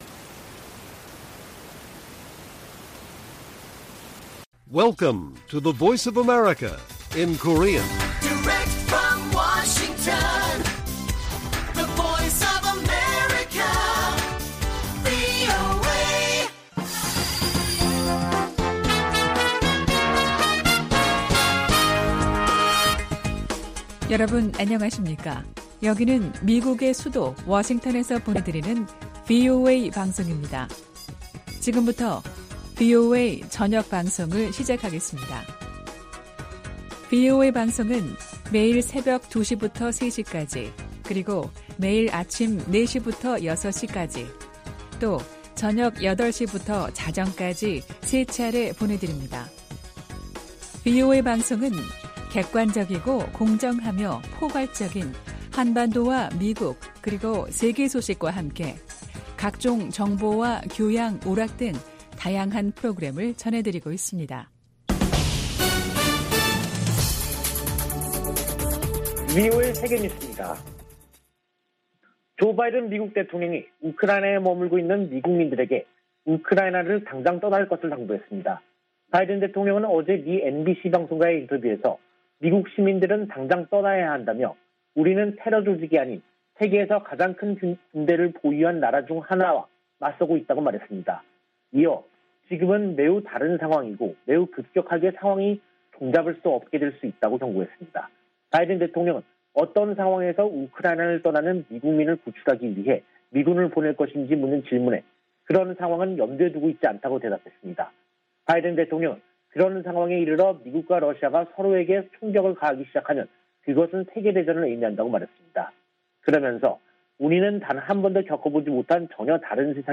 VOA 한국어 간판 뉴스 프로그램 '뉴스 투데이', 2022년 2월 11일 1부 방송입니다. 토니 블링컨 미 국무부 장관은 4개국 안보협의체 '쿼드(Quad)'가 공통 도전에 대응하고 번영 기회를 찾는 것이 목표라고 밝혔습니다. 미국은 북한의 잘못된 행동을 막기 위해 유엔 회원국들이 한 목소리를 내야 한다고 밝혔습니다. 미국과 한국이 지난 2018년 이후 중단된 대규모 실기동 연합훈련을 재개해야 한다고 미 해병대사령관이 밝혔습니다.